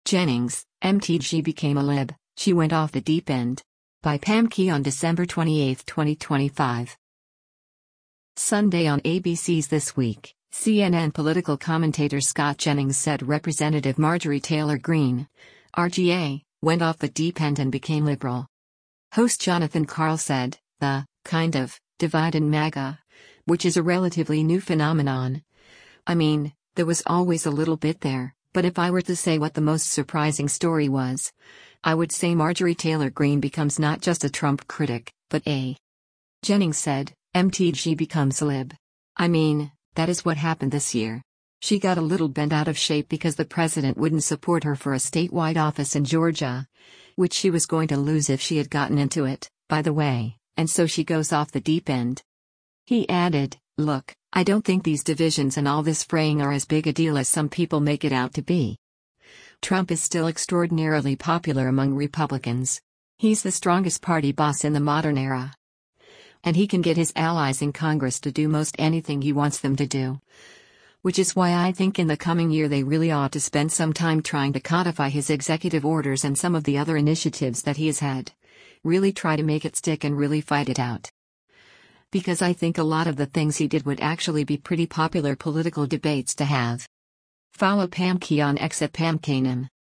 Sunday on ABC’s “This Week,” CNN political commentator Scott Jennings said Rep. Marjorie Taylor Greene (R-GA) went “off the deep end” and became liberal.